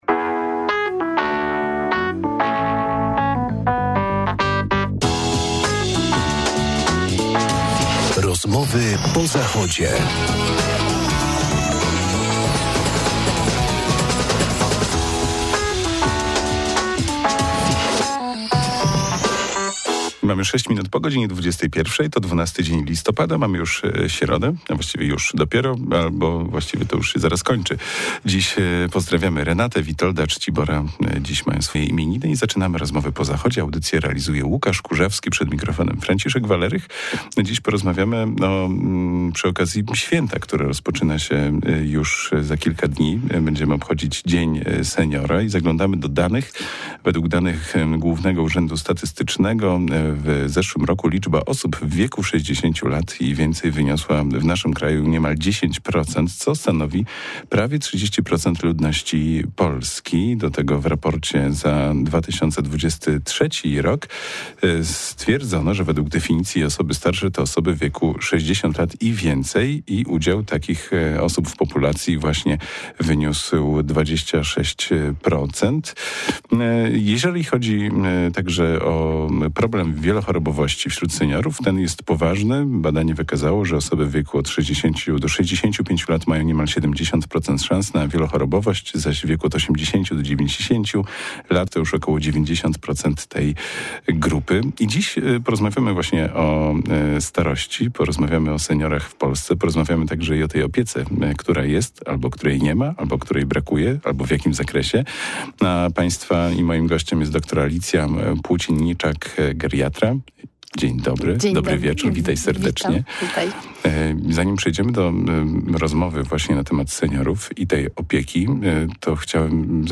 Z okazji Dnia Seniora w naszym radiowym studiu gościliśmy lekarkę zajmującą się na co dzień geriatrią i opieką długoterminową w zakładzie opiekuńczo-leczniczym.